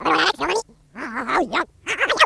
Alien Voices Reversed, Fast